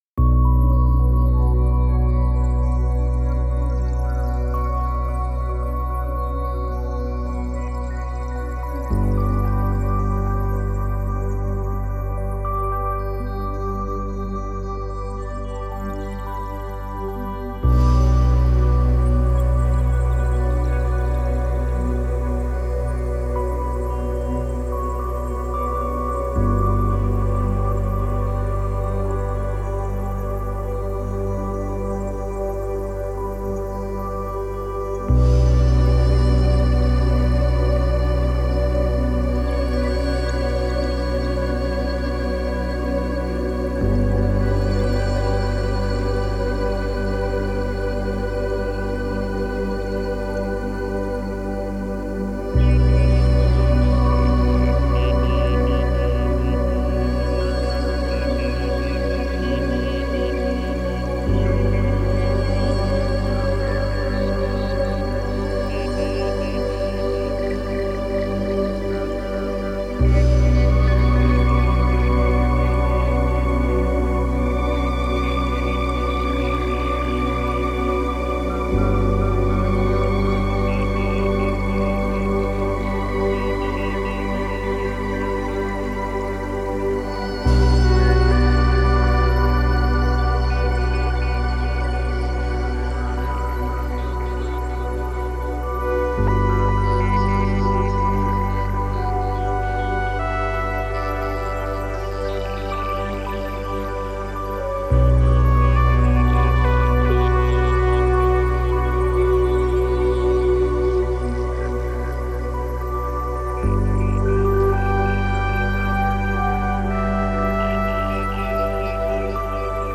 Ambient Chill Out / Lounge Cinematic / FX Multi-genre Synthwave / Retrowave
RHEA is a sonic journey beyond the familiar, where deep atmospheric pads, mystical textures, and expressive guitar tones create a space for inspiration. Each sound is filled with depth, emotion, and otherworldly nuances, making it perfect for ambient and chill-out music.
66 Atmosphere Spire Presets